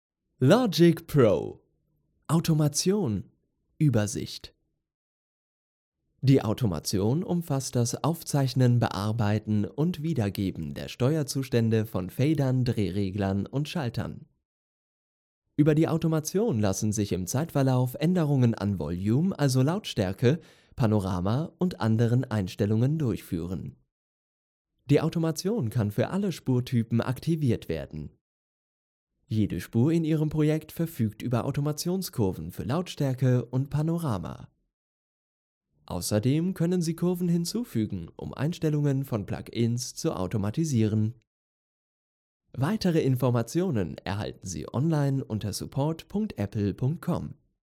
Sprechprobe: eLearning (Muttersprache):
Noted for his authentic, friendly & warm personas. A versatile performer with a variety of styles.